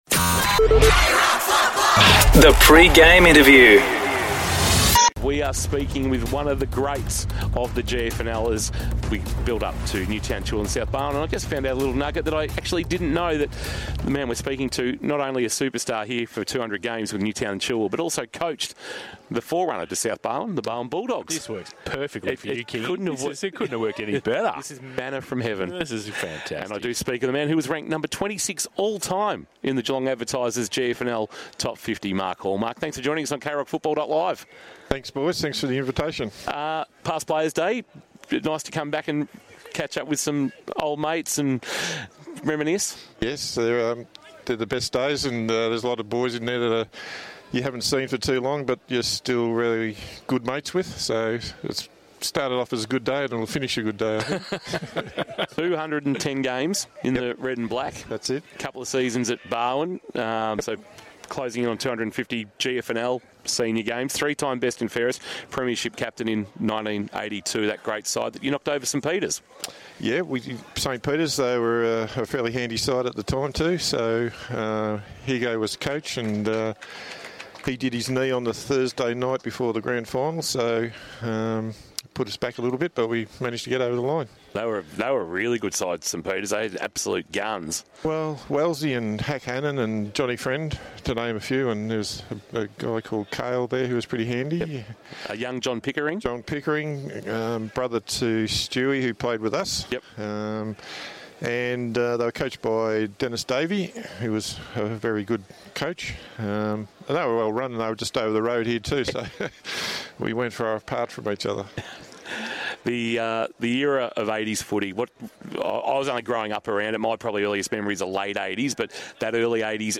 Pre-game interview